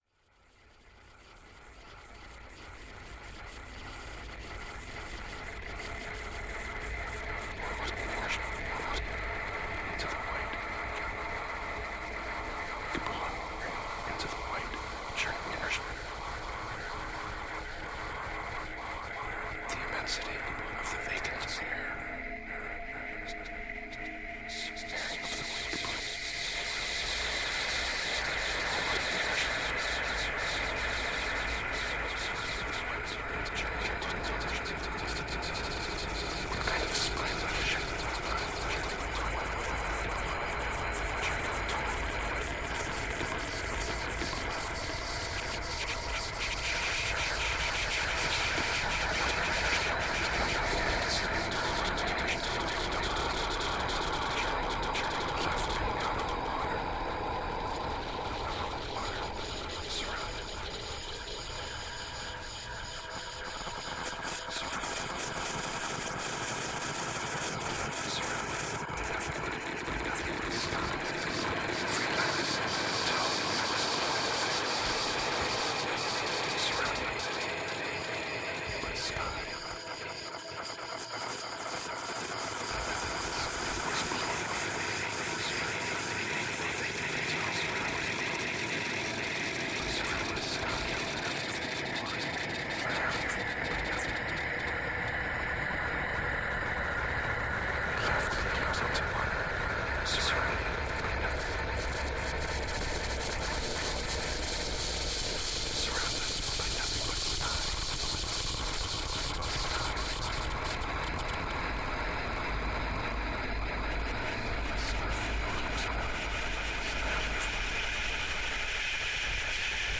Audio Example: Mixture of direct and processed sound
06_Granular_plus_voice.mp3